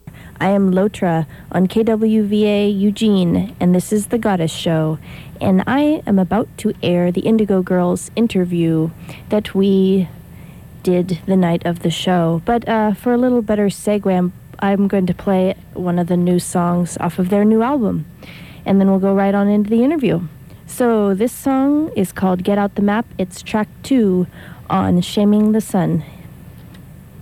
lifeblood: bootlegs: 1997-04-04: kwva - eugene, oregon
03. announcer (0:27)